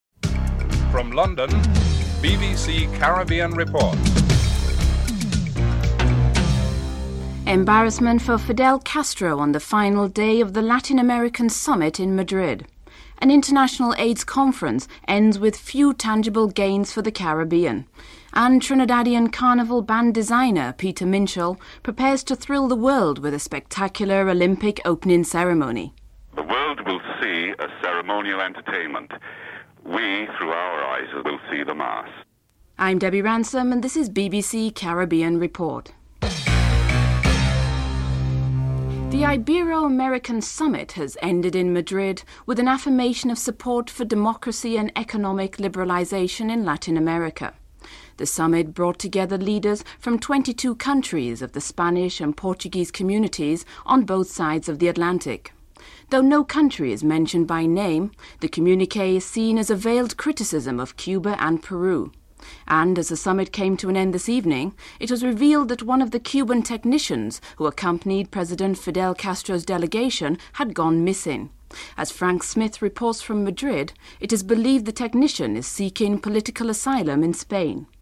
The British Broadcasting Corporation
1. Headlines (00:00-00:38)
6. Recap of the headlines (14:04-14:40)